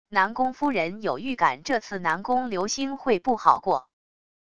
南宫夫人有预感这次南宫流星会不好过wav音频生成系统WAV Audio Player